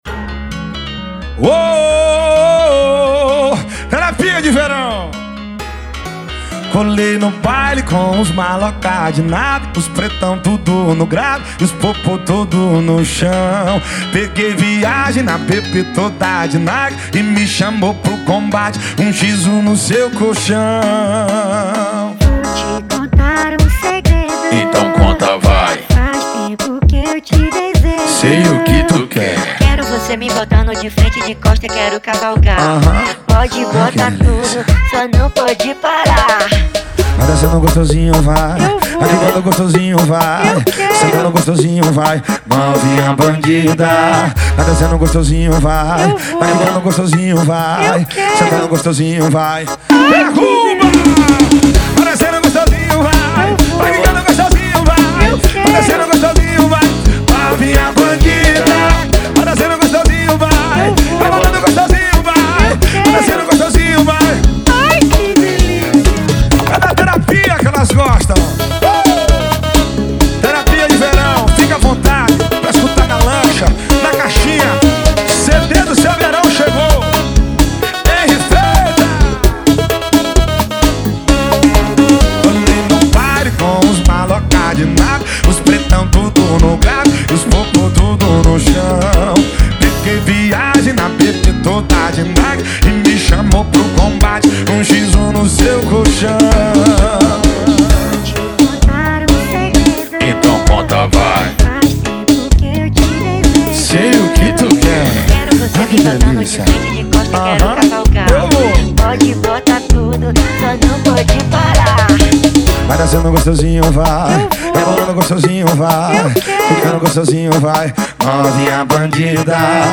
2024-02-14 18:21:06 Gênero: Forró Views